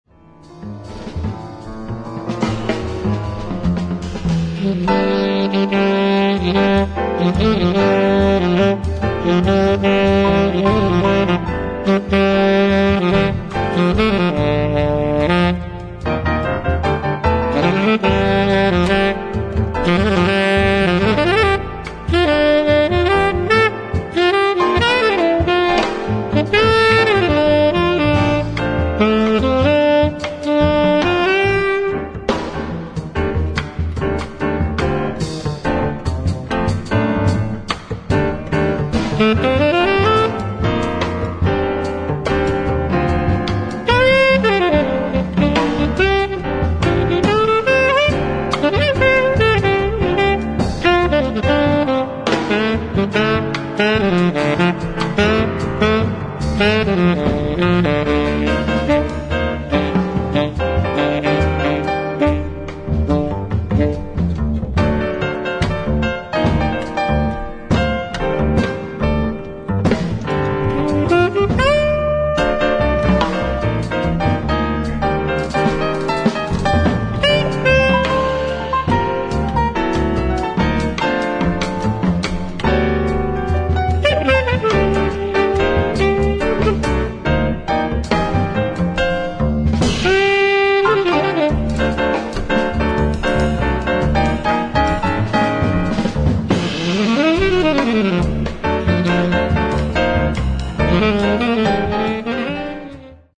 ライブ・アット・ヴィクトリア・ホール、ジュネーブ、スイス 04/27/2007
異次元の超高音質！！
※試聴用に実際より音質を落としています。